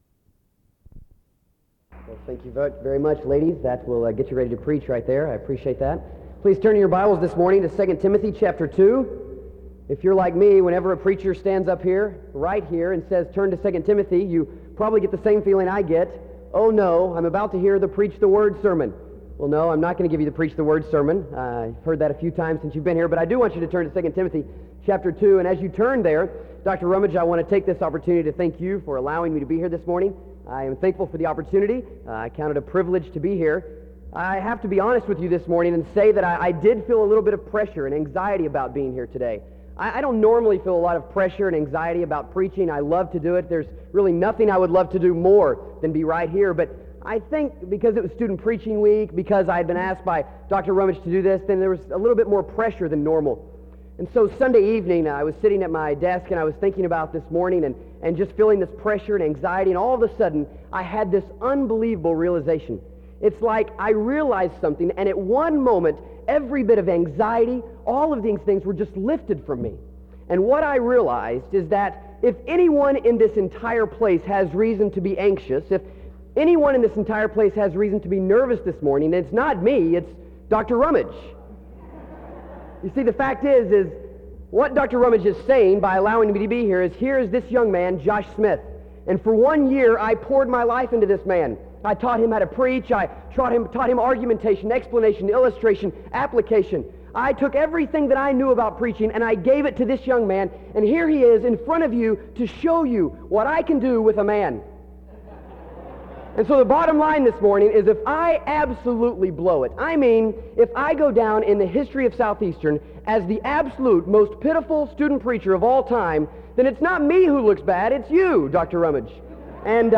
In Collection: SEBTS Chapel and Special Event Recordings - 2000s